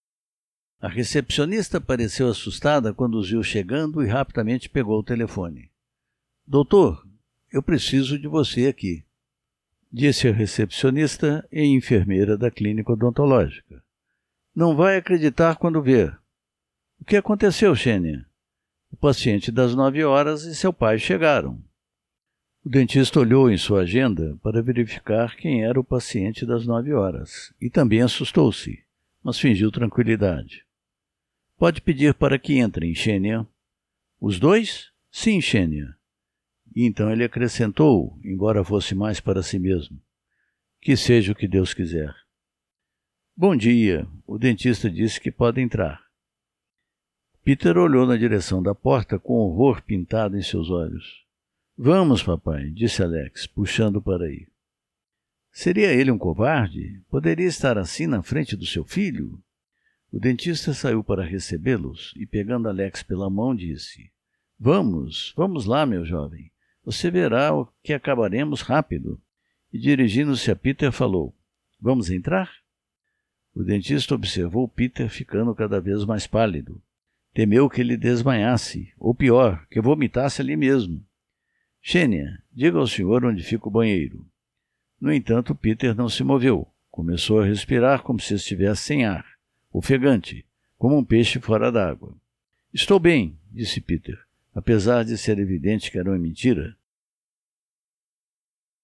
Baixe as primeiras páginas em formato de áudio livro